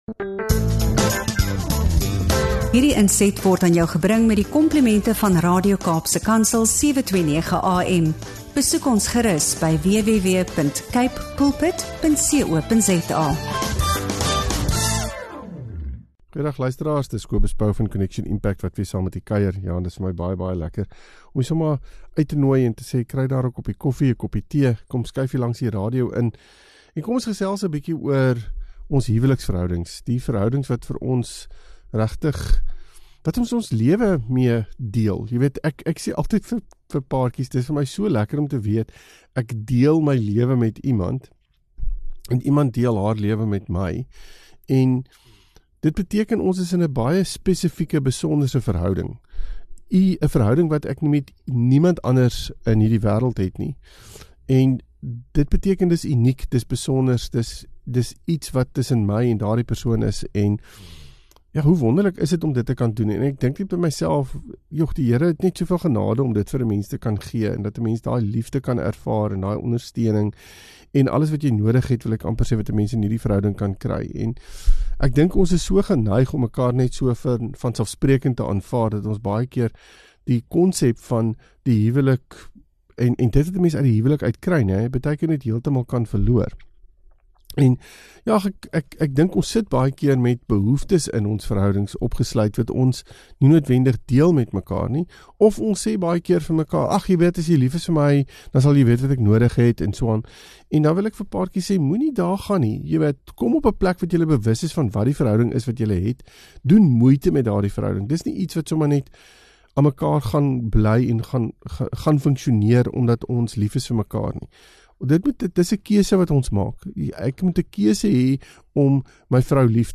Hy gee riglyne oor hoe vroue kan kommunikeer wat hulle verwag en hoe mans hul selfvertroue en waarde kan bou in die huwelik. Dis 'n eerlike en hartlike gesprek oor die dinamika van respek en hoe vroue hul mans se behoeftes beter kan verstaan.